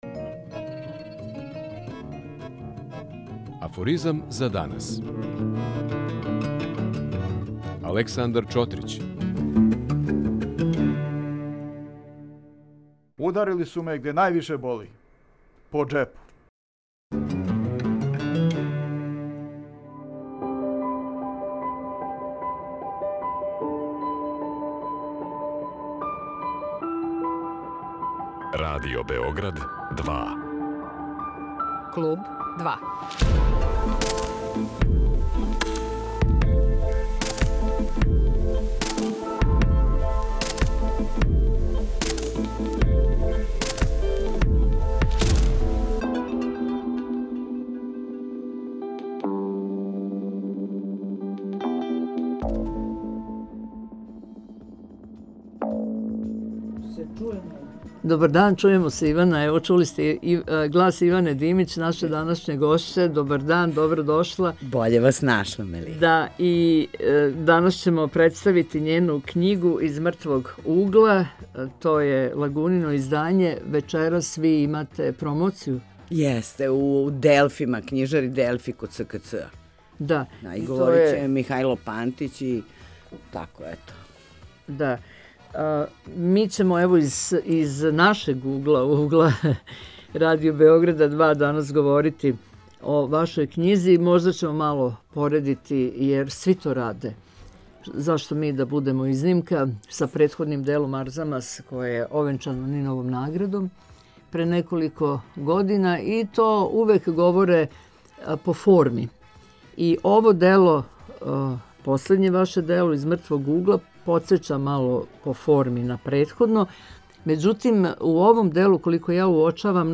Гошћа Клуба 2 је списатељица Ивана Димић а говоримо о њеној новој књизи „Из мртвог угла”.